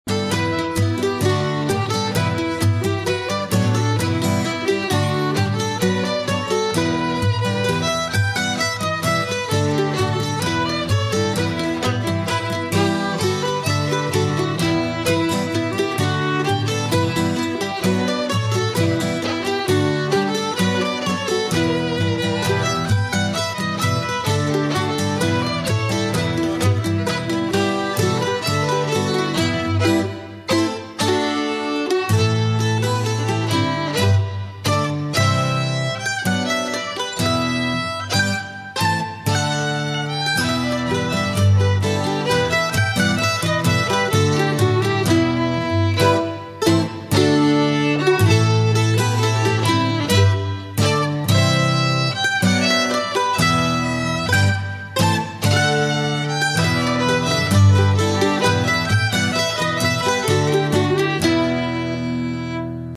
Key: Bm/G
Form: Reel*
Region: England